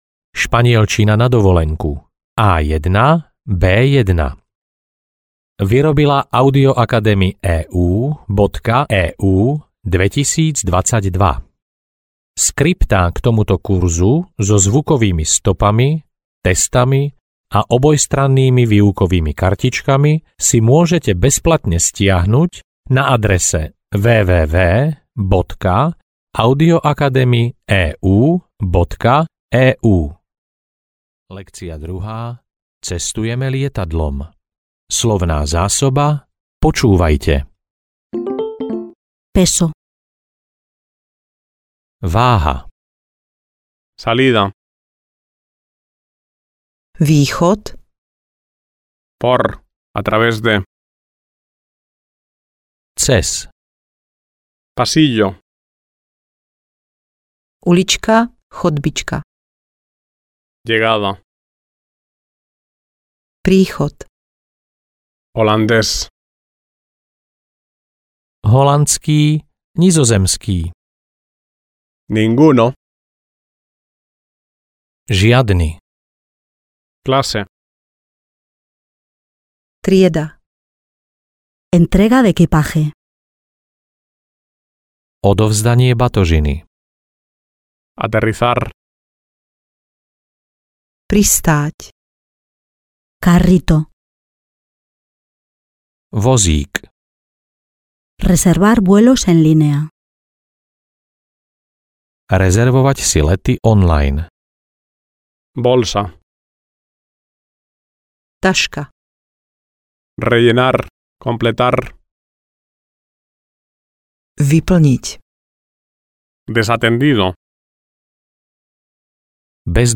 Španielčina na cesty A1-A2 audiokniha
Ukázka z knihy
Keď zvládnete preklad viet zo slovenčiny do španielčiny (lekcia 6) v časovej medzere pred španielským prekladom, máte vyhraté.